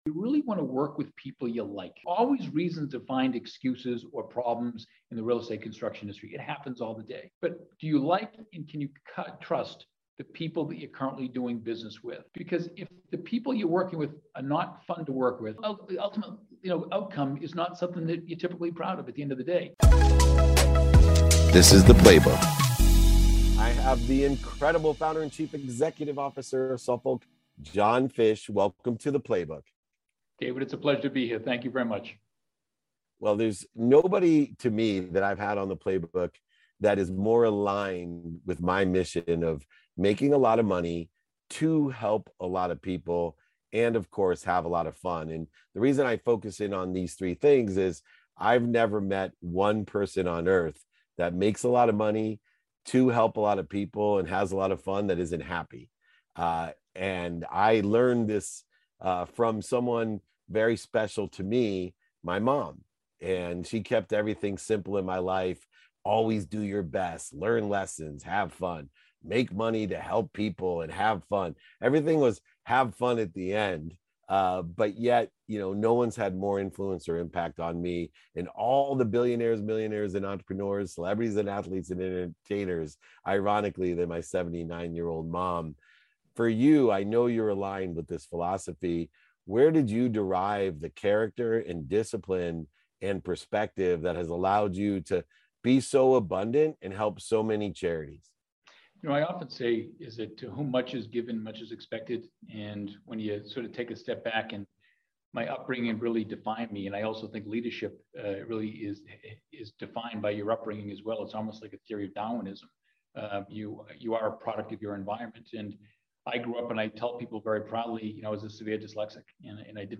Surrounding Yourself With The Right People | Interview